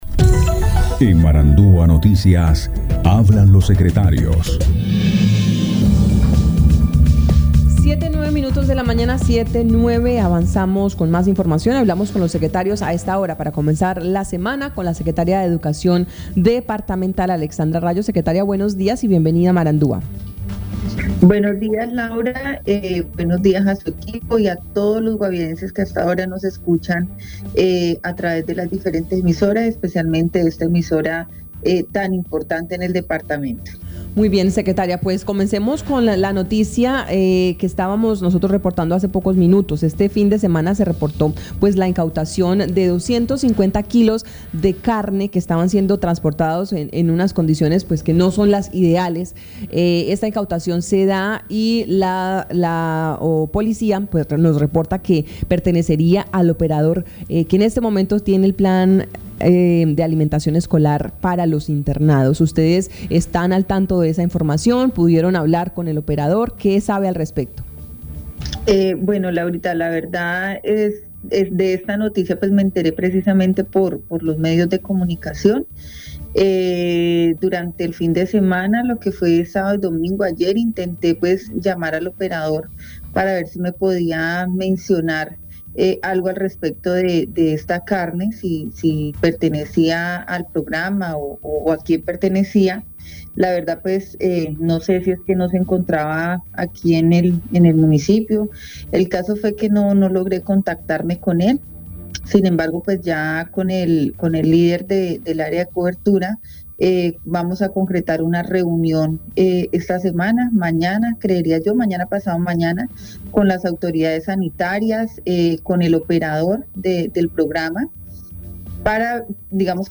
Escuche a Alexandra Rayo, secretaria de Educación del Guaviare.